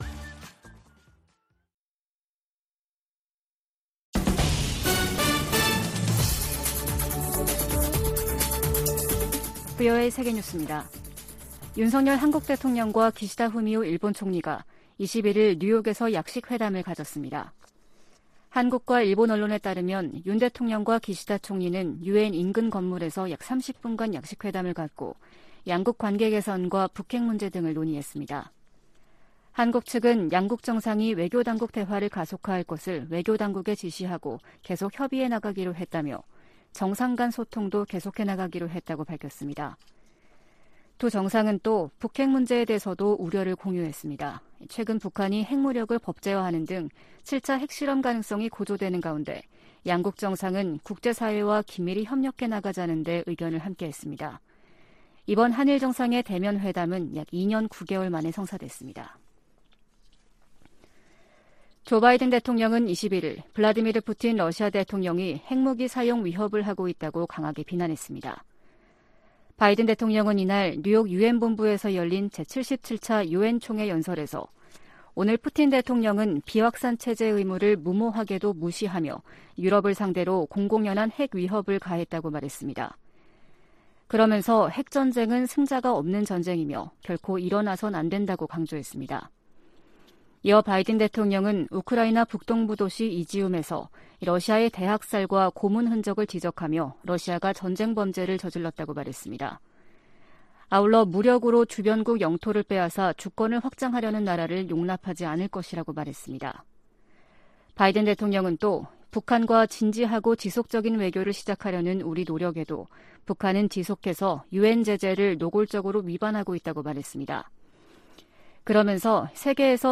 VOA 한국어 아침 뉴스 프로그램 '워싱턴 뉴스 광장' 2022년 9월 22일 방송입니다. 조 바이든 미국 대통령이 유엔총회 연설에서 유엔 안보리 개혁의 필요성을 강조할 것이라고 백악관이 밝혔습니다. 윤석열 한국 대통령은 유엔총회 연설에서 자유를 지켜야 한다고 역설했습니다.